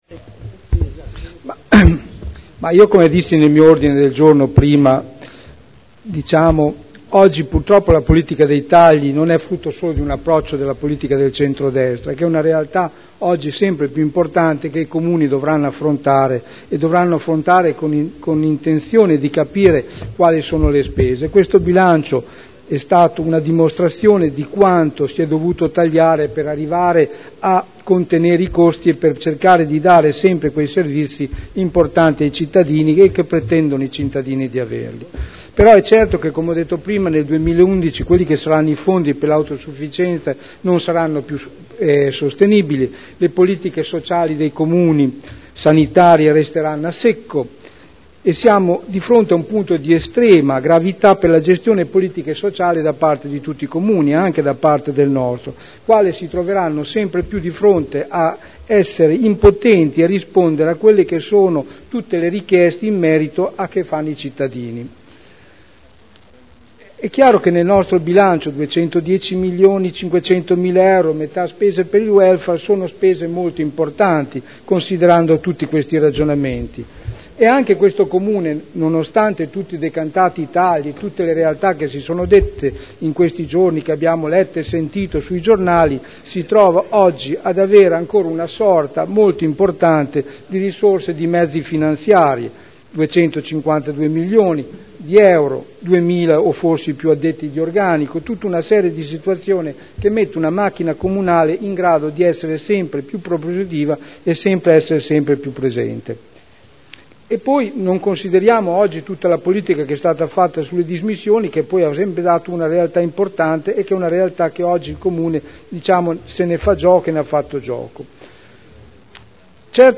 Seduta del 28/03/2011. Dibattito sul Bilancio.